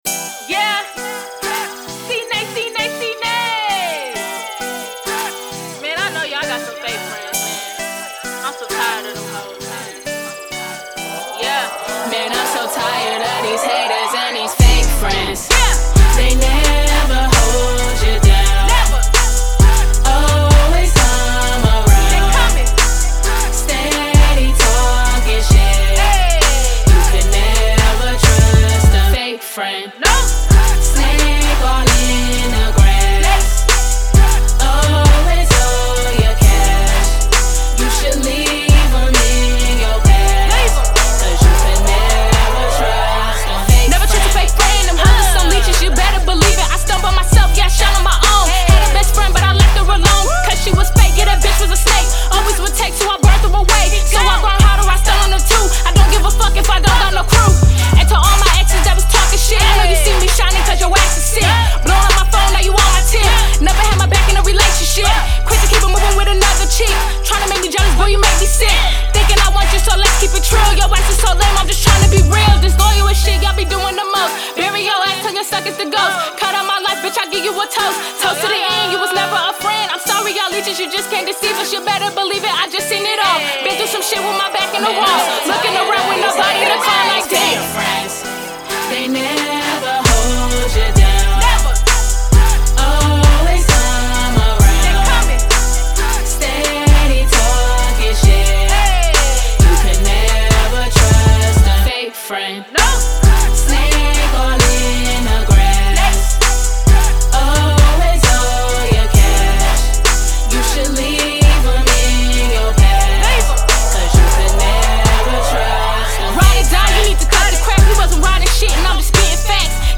Hiphop
laced with amazing RnB influences